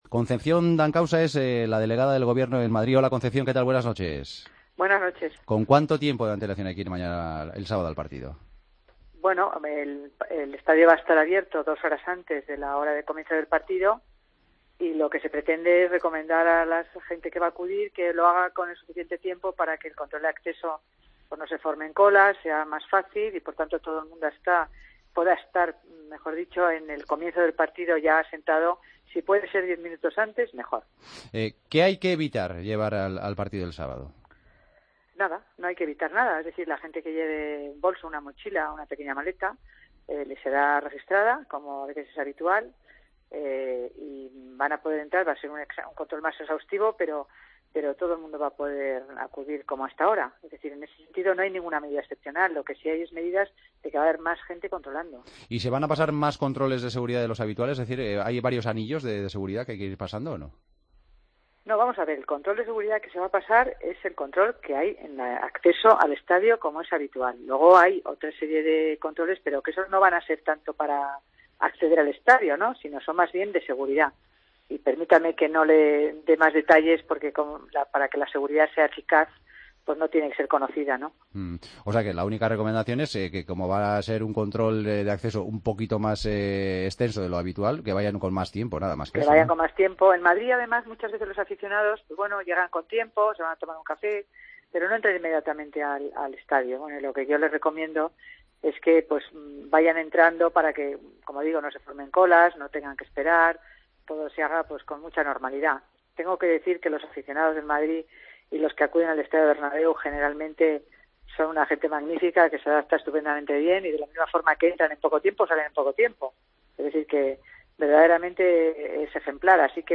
La delegada del Gobierno en Madrid nos habló de las medidas de seguridad que se van a tomar en el Santiago Bernabéu este sábado con motivo del clásico: "El estadio estará abierto dos horas antes y se trata de recomendar a la gente que vaya con el tiempo suficiente.